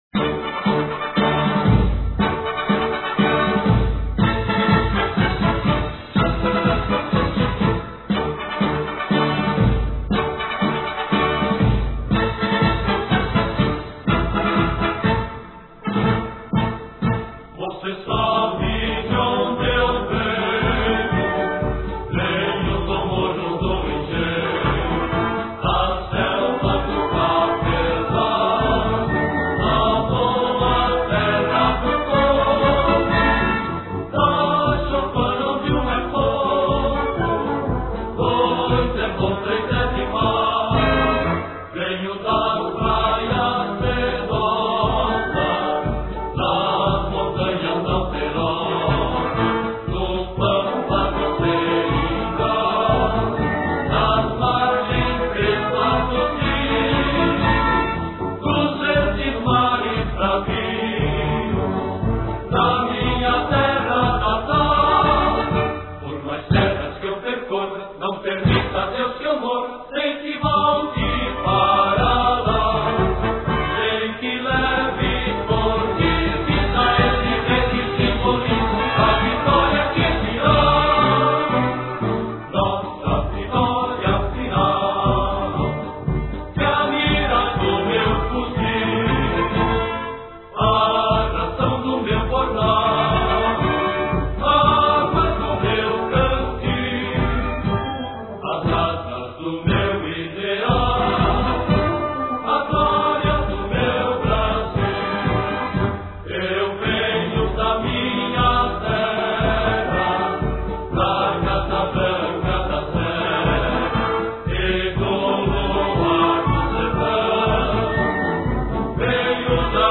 Exército Brasileiro
hino.cancao.do.expedicionario.wav